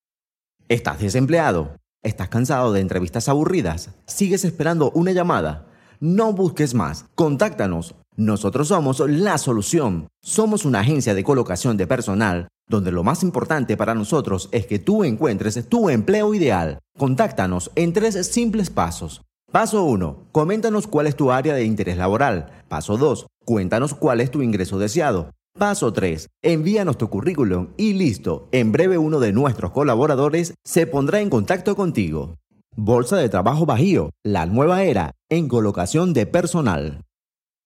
Voz off para video corporativo Bolsa de Trabajo Bajio